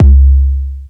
100 Kick Power.wav